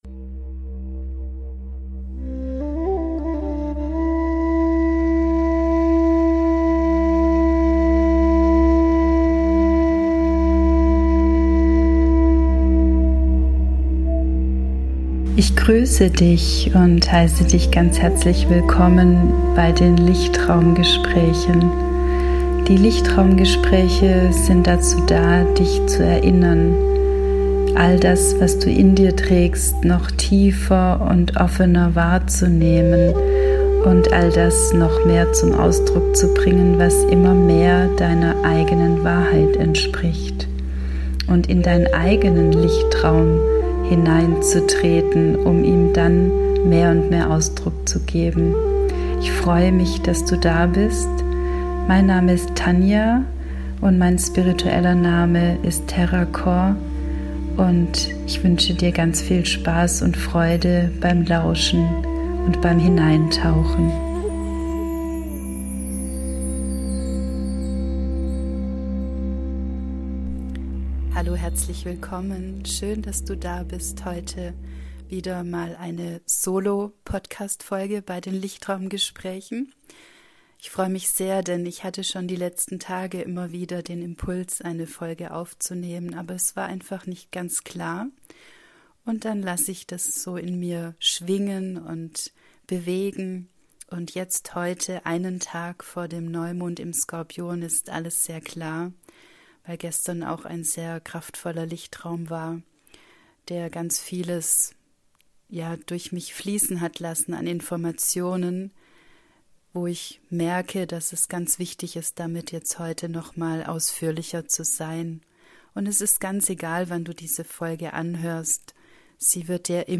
Heute einmal wieder eine Solo-Podcastfolge für Dich. Ich teile mit Dir darin all das zum Thema Dunkelheit was im Moment wichtig ist, wahrzunehmen. Es geht nicht darum sich der Dunkelheit zu entziehen und davor zu fliehen.